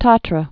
(tätrə)